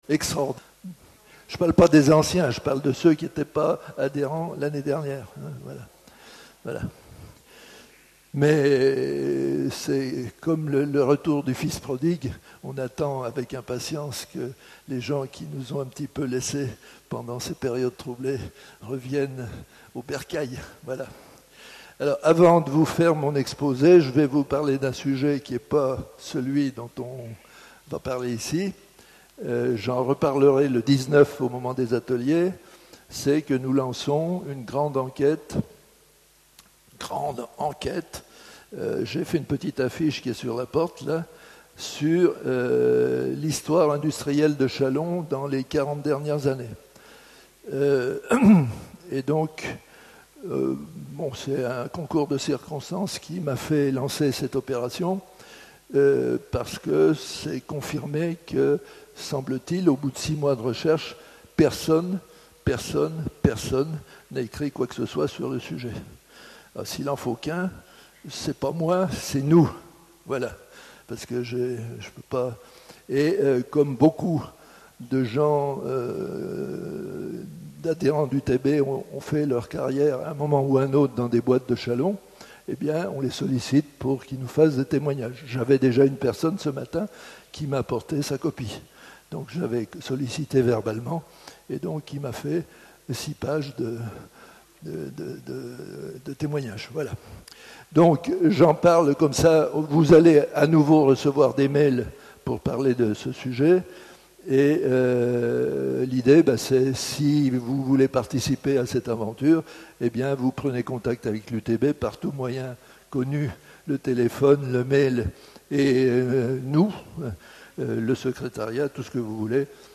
Maison des syndicats GRATUIT OUVERT A TOUS Comme chaque année, nous présenterons la saison des conférences prévue pour la saison 2023-2024.